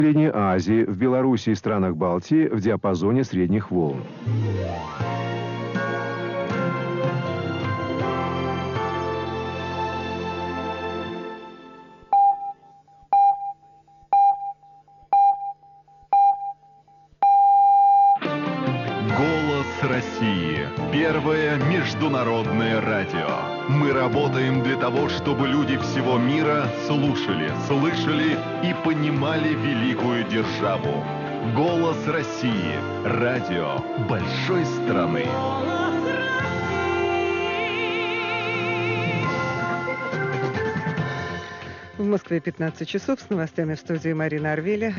Начало новостей (Голос России, 08.12.2009)